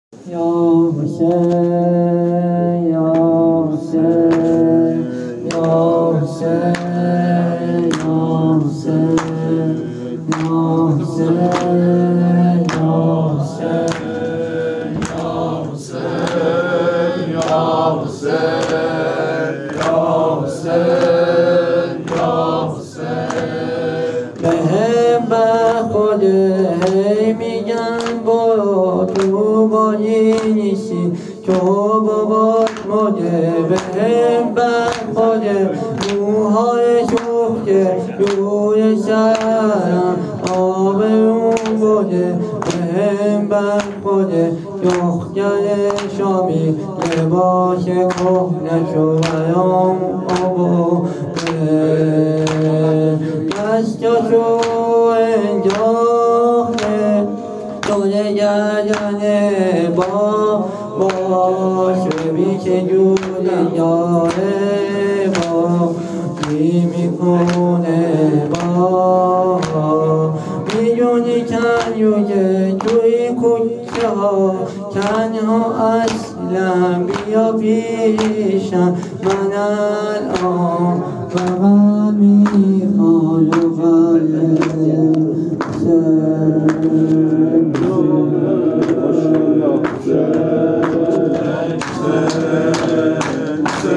زمینه شب سوم
هیت هفتگی عشاق العباس تهران